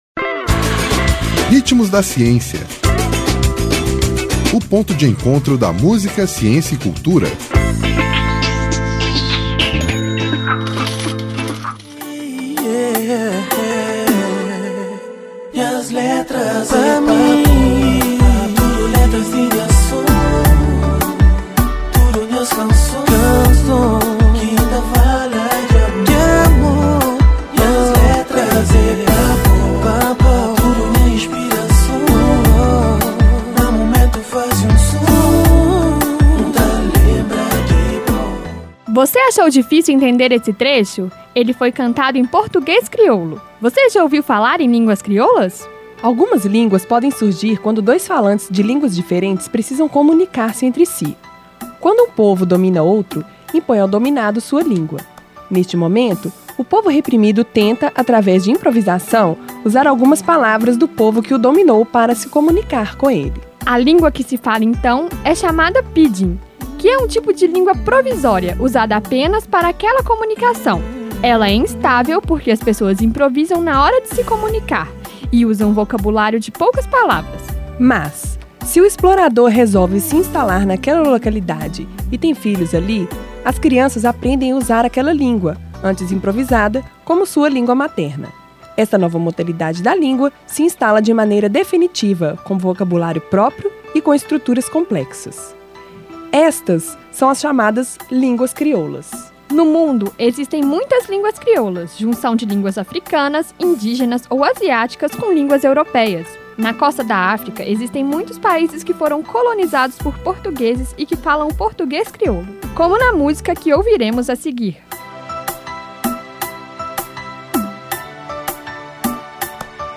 Ele foi cantado em português crioulo! Você já ouviu falar em línguas crioulas?…